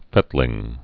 (fĕtlĭng)